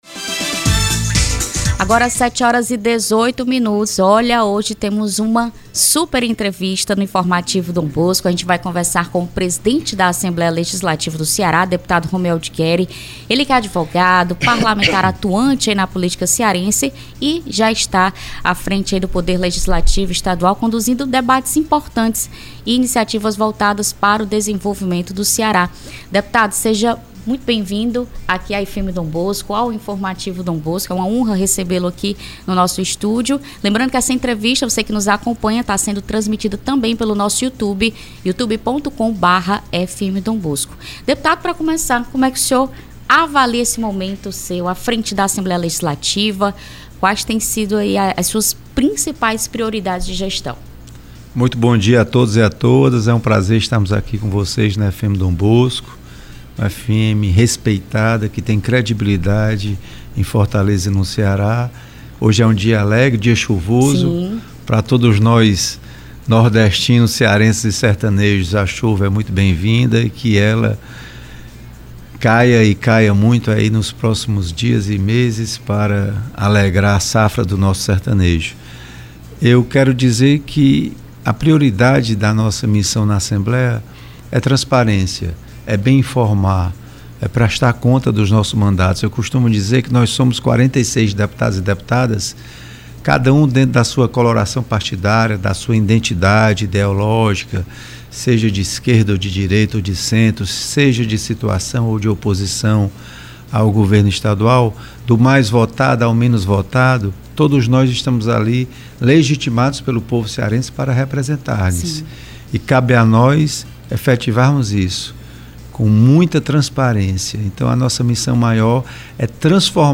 O presidente da Assembleia Legislativa do Ceará (Alece), Romeu Aldigueri, concedeu entrevista hoje (06) à FM Dom Bosco e anunciou a abertura de 200 vagas iniciais diretas para o concurso da Alece.
ENTREVISTA-PRESIDENTE-DA-ALECE.mp3